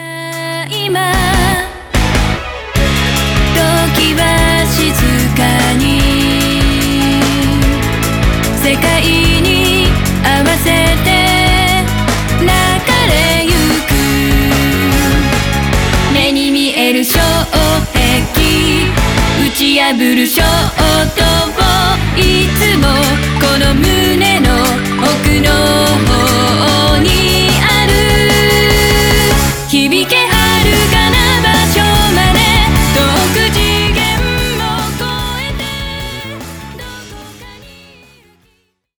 J-POP ROCK